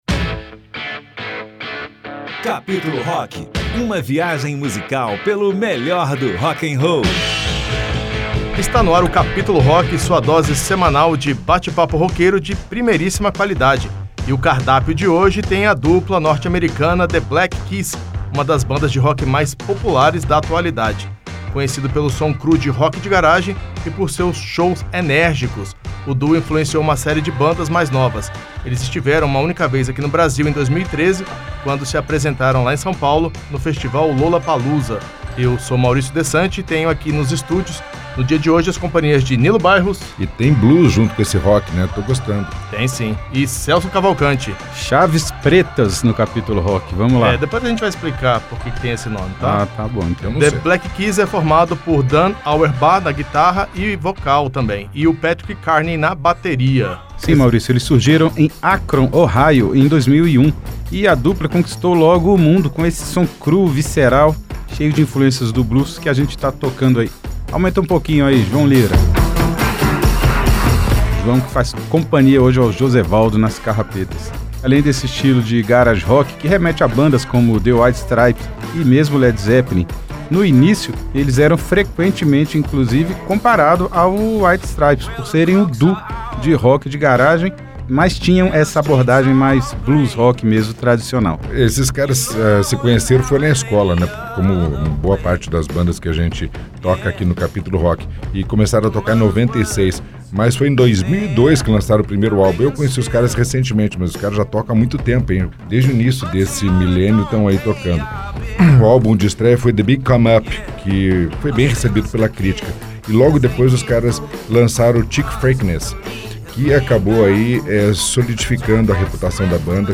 na guitarra e vocal